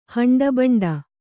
♪ haṇḍa baṇḍa